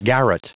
Prononciation du mot garret en anglais (fichier audio)
Prononciation du mot : garret